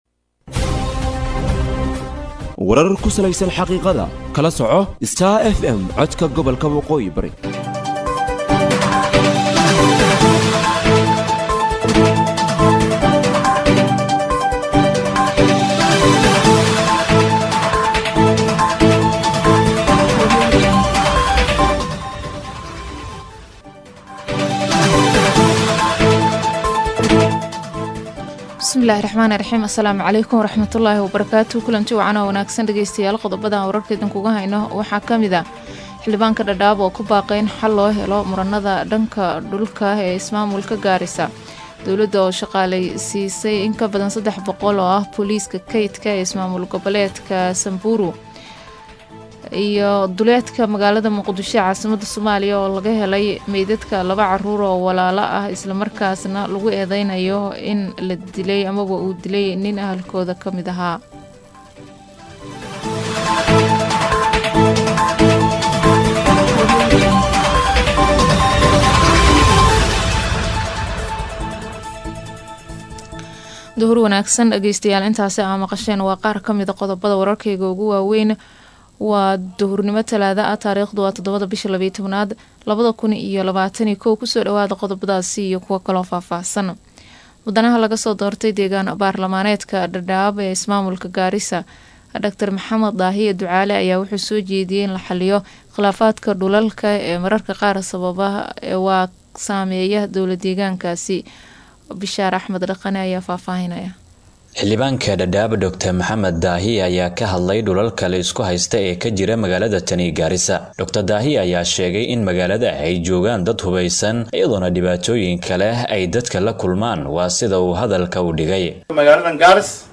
DHAGEYSO:DHAGEYSO:WARKA DUHURNIMO EE IDAACADDA STAR FM